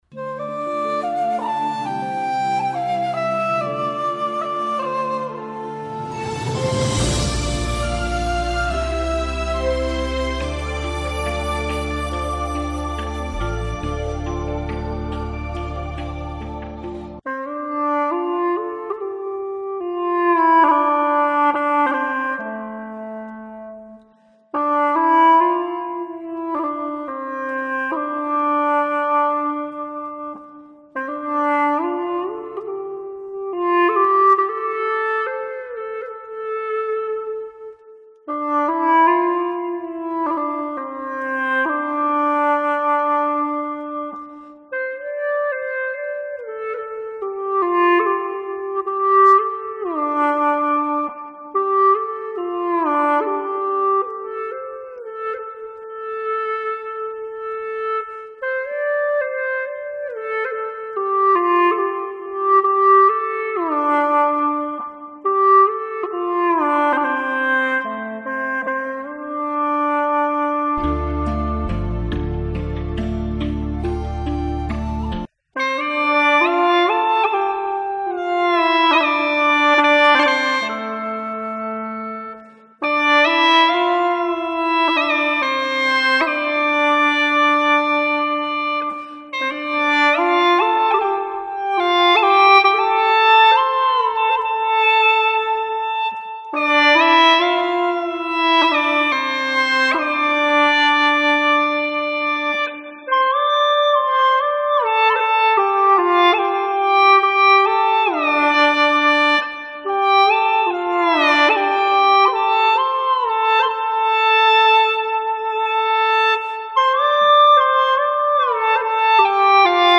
调式 : F 曲类 : 影视